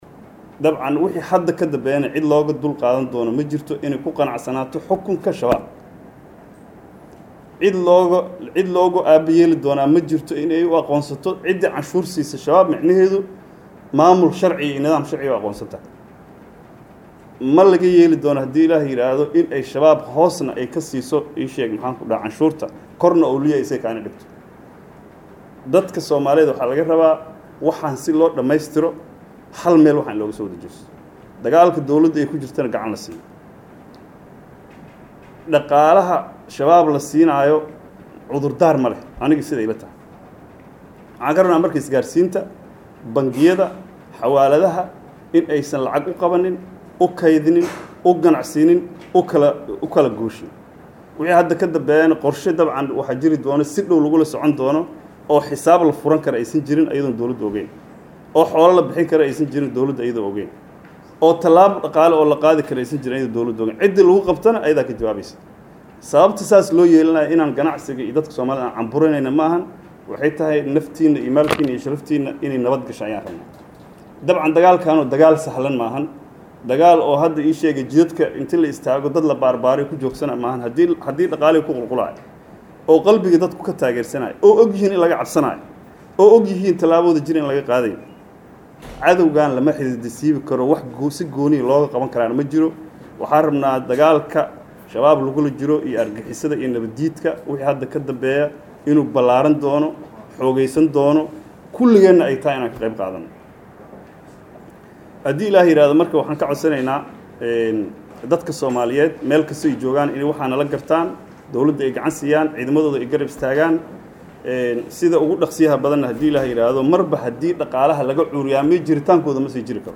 Taliyaha-Nabad-Sugida-Soomaaliya.mp3